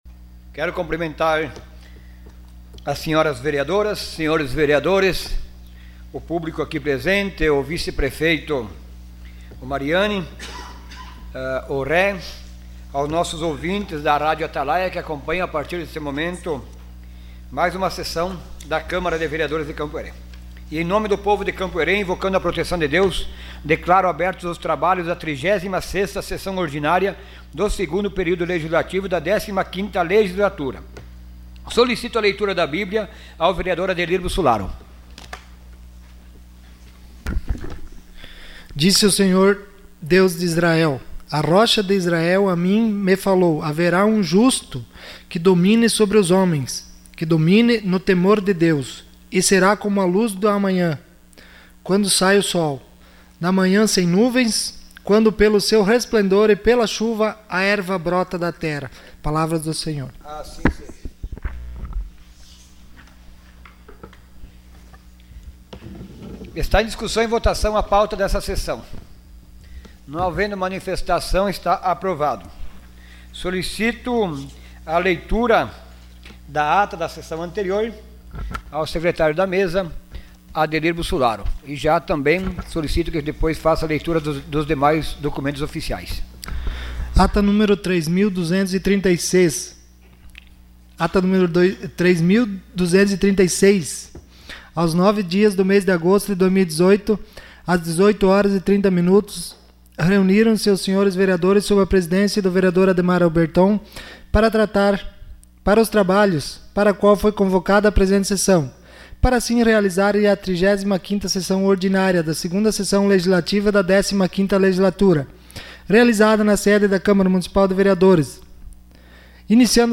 Sessão Ordinária dia 15 de agosto de 2018.